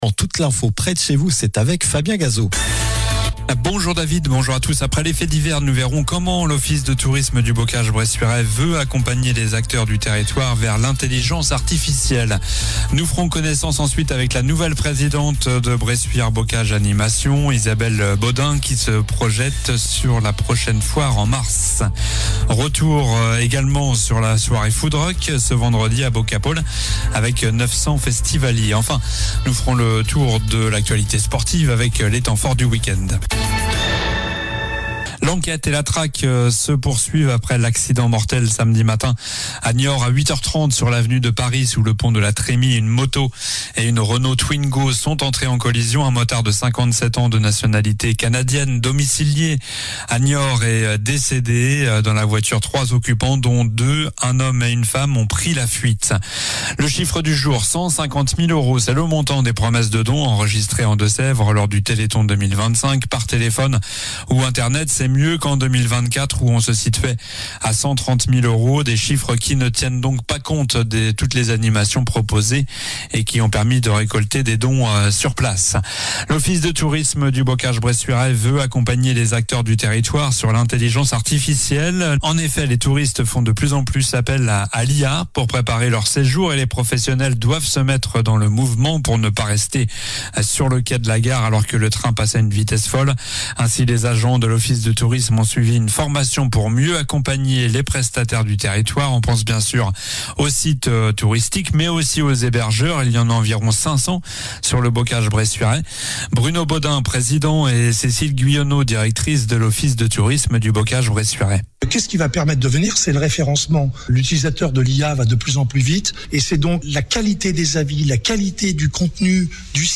Journal du lundi 8 décembre (midi)